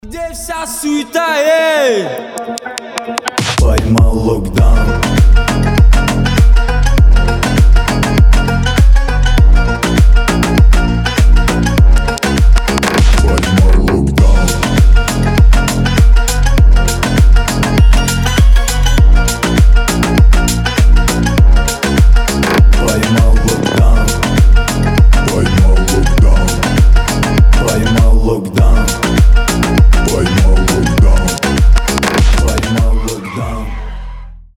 пацанские
басы
восточные